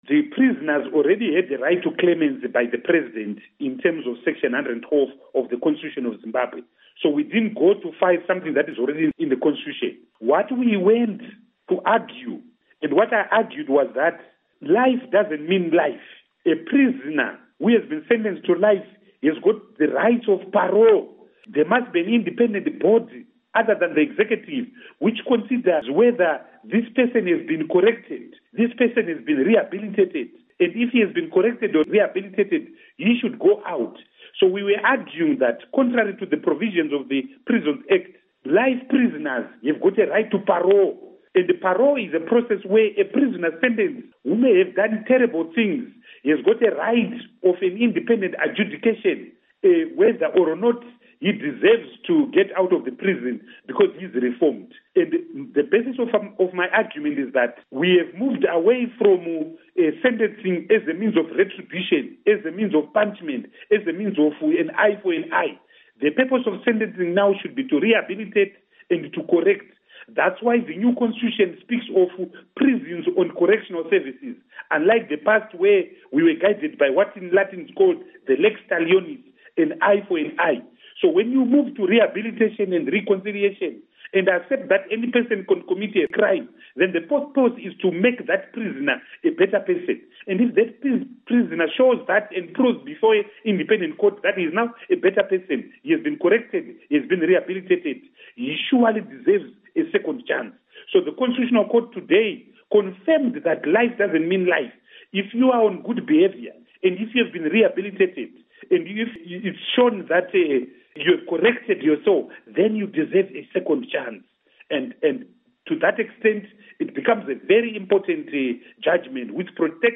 Interview With Tendai Biti